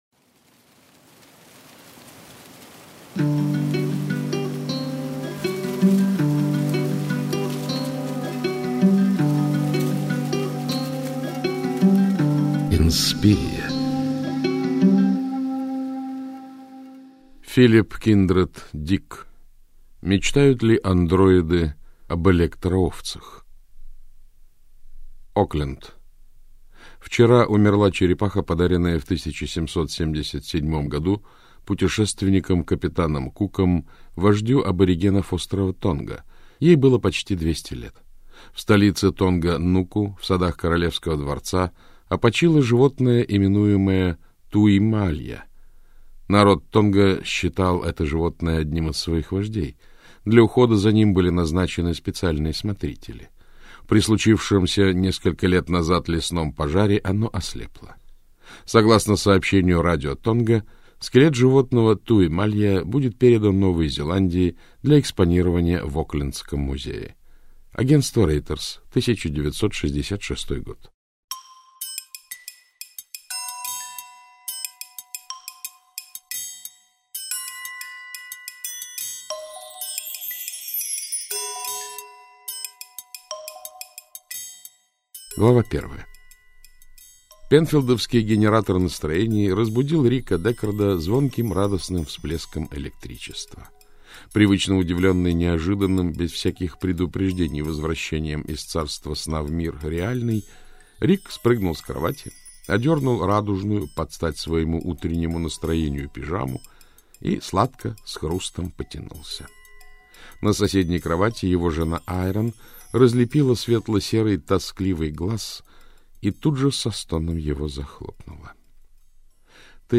Аудиокнига Мечтают ли андроиды об электроовцах?